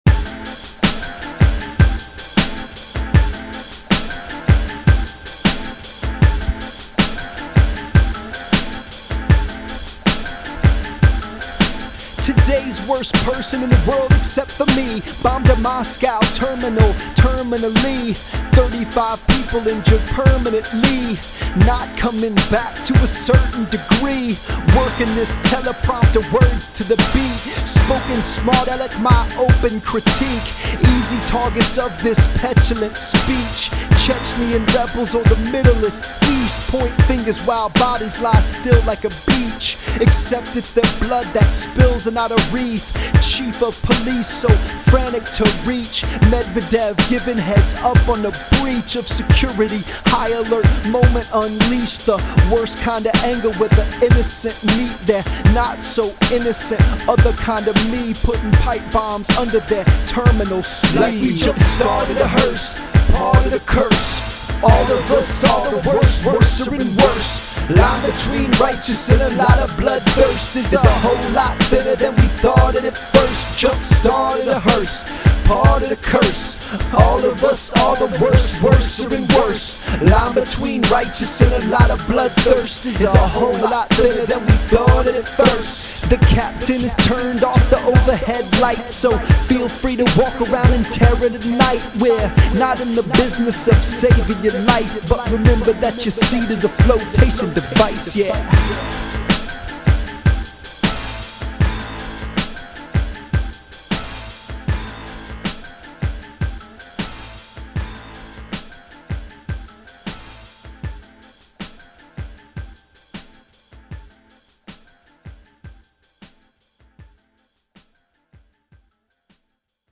Themes include belligerent use of a teleprompter to accuse and mock admittedly wicked events.